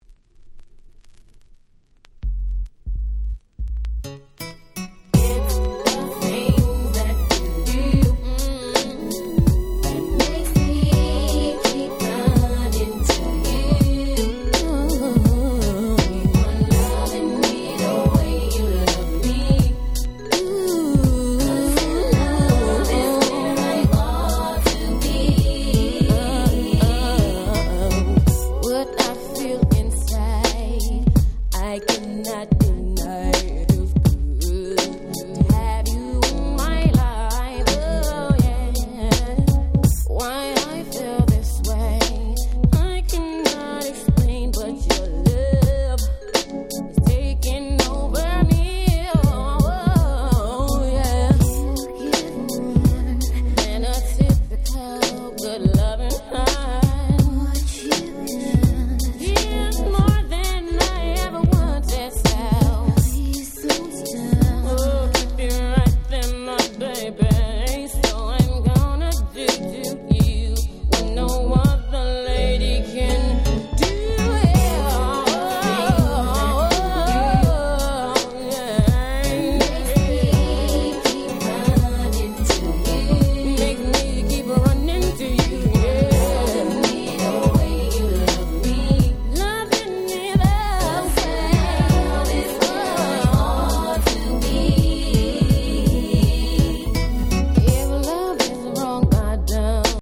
Nice Hip Hop Soul♪